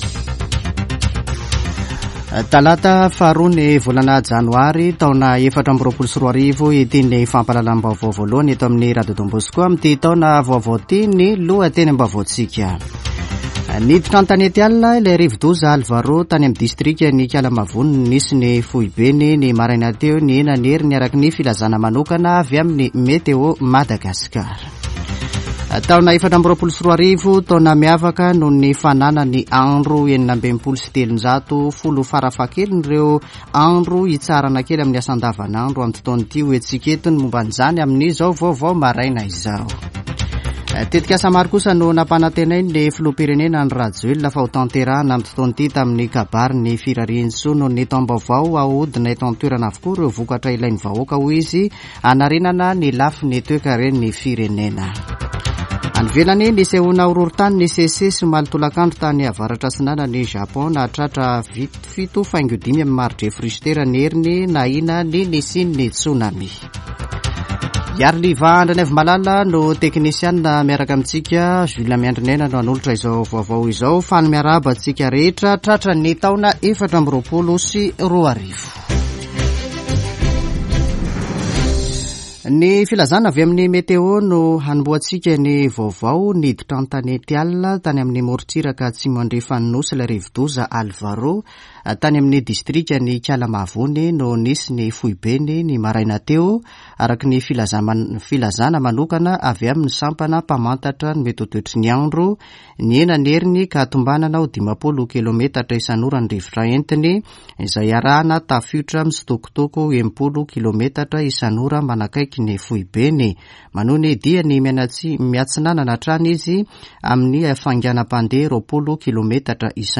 [Vaovao maraina] Talata 2 janoary 2024